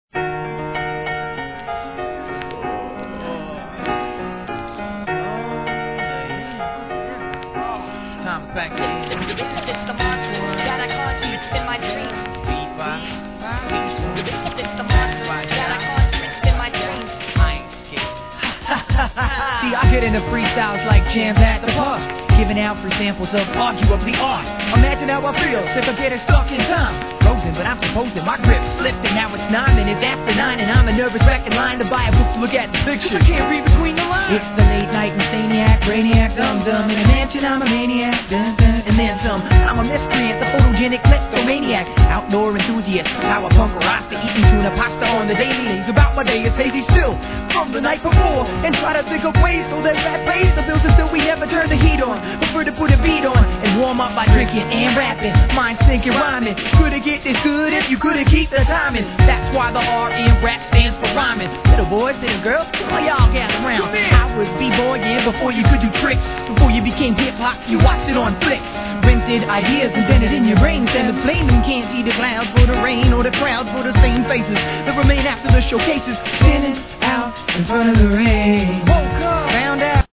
HIP HOP/R&B
(サンプリングによるチリ)